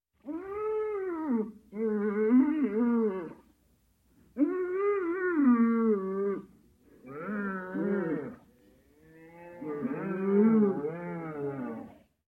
Стон теленка в хлеву